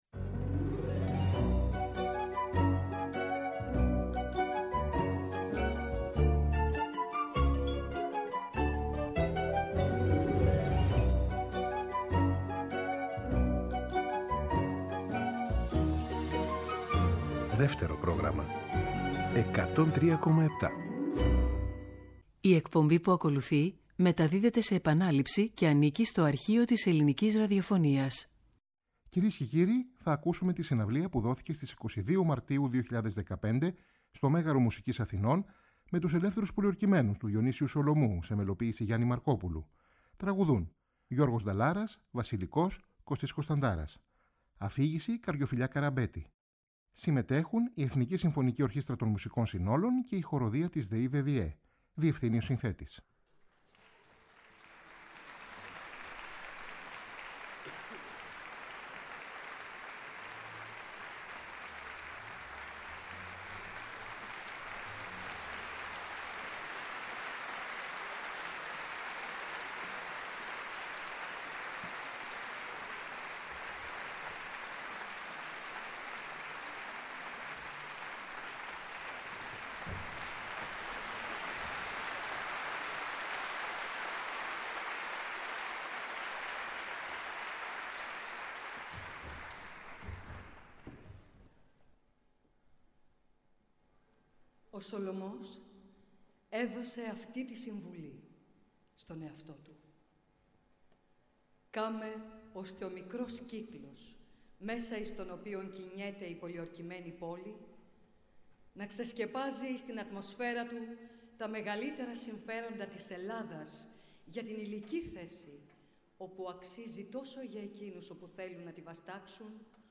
Διευθύνει ο συνθέτης.